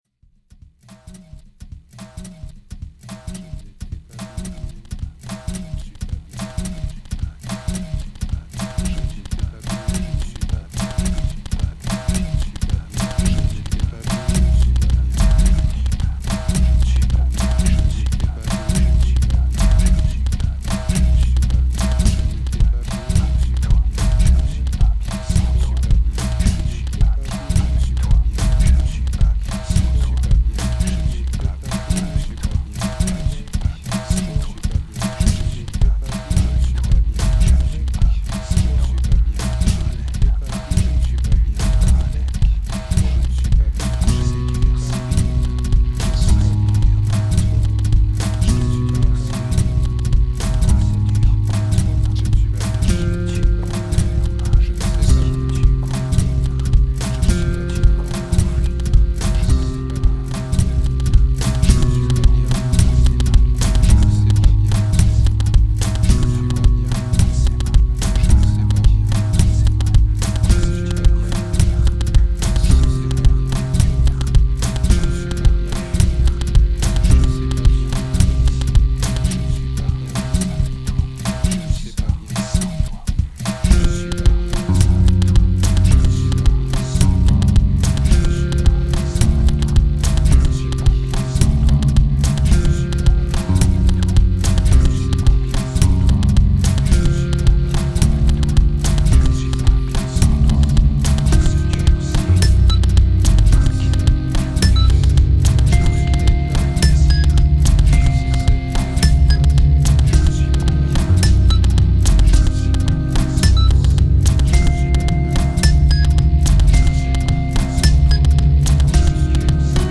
Dope Discard Medication Blues Simplistic Instrumental Quiet